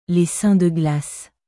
Les saints de glaceレ サン ドゥ グラス